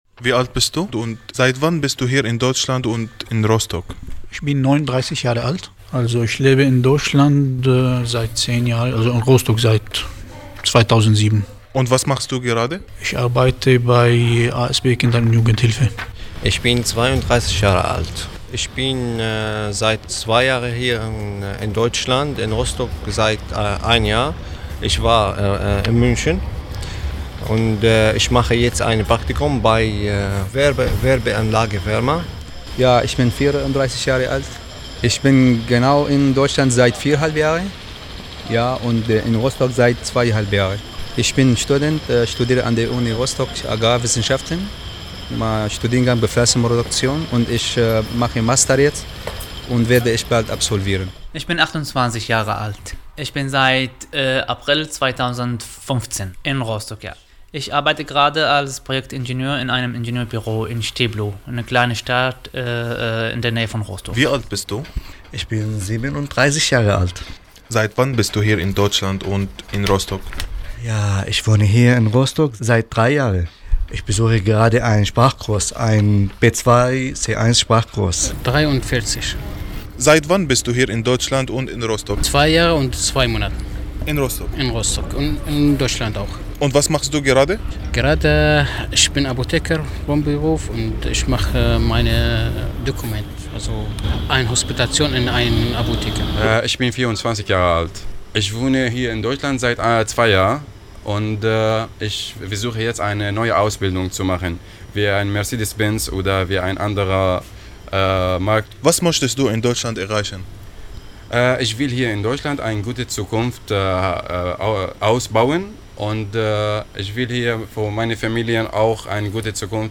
Nachgefragt unter Flüchtlingen: